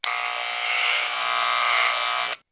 haircut1.wav